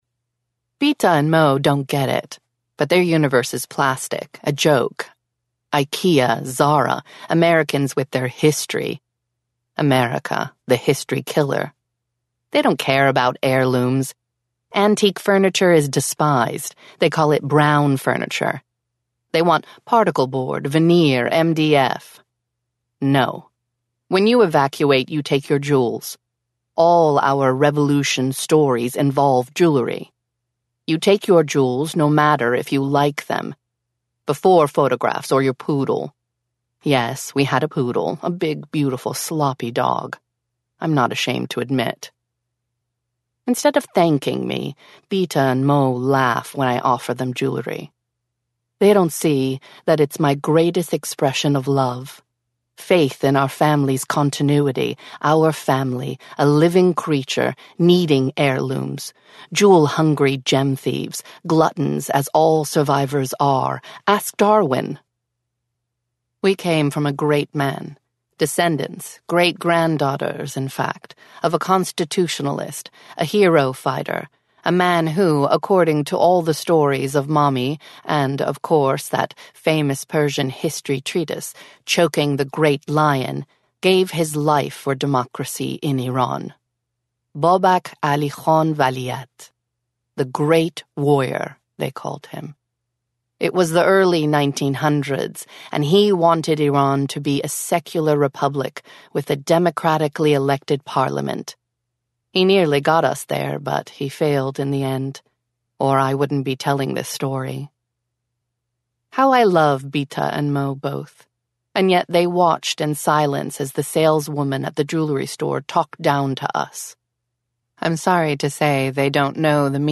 Audiobook sample
The-Persians_audio-extract.mp3